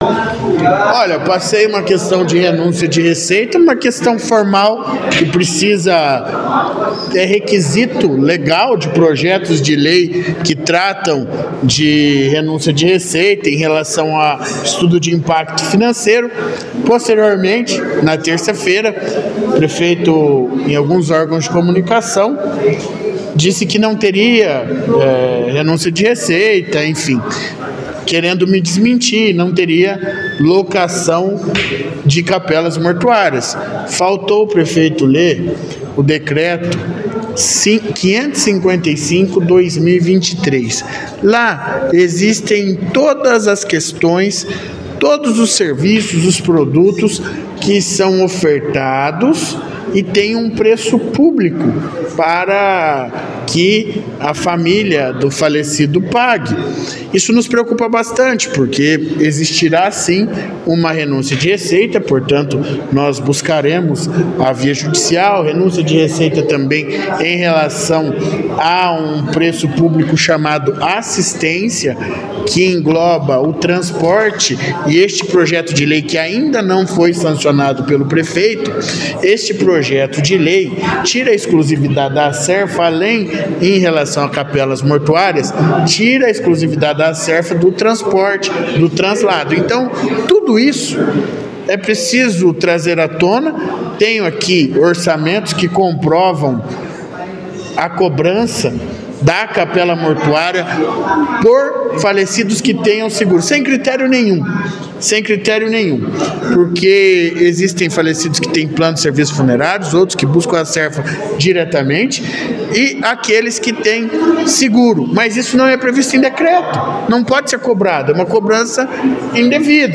• Acompanhe as entrevistas realizadas com os vereadores após a sessão que teve a participação on-line de Odarlone Orente e as faltas justificadas, de Tiago Cordeiro e Guilherme Livoti.